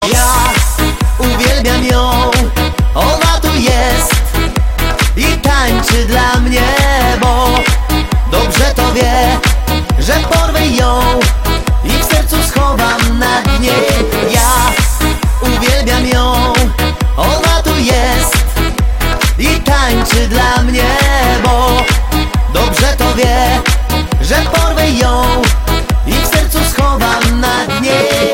Disco polo